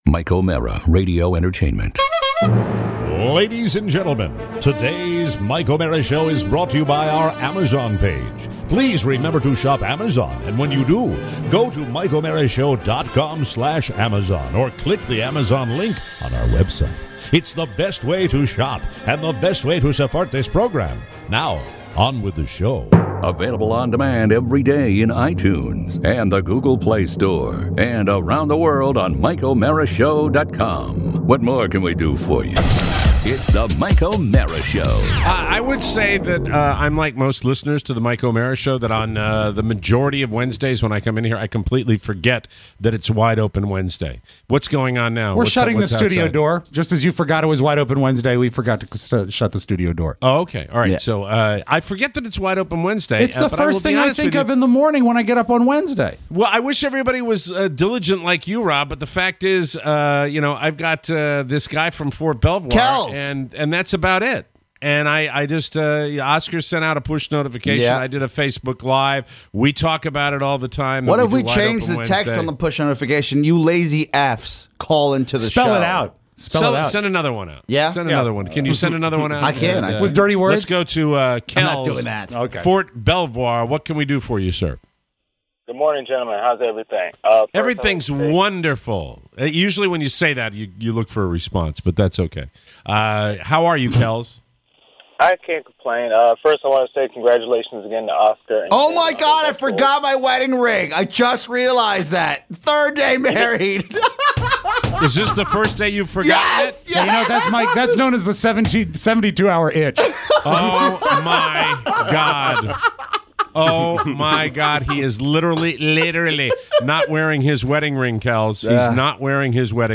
It’s Wide Open Wednesday and we’re taking your very best calls!